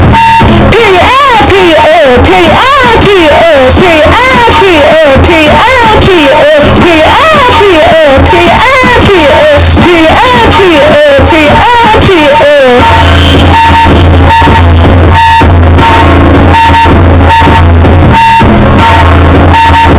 Period Ah Period Uh ( Very Loud )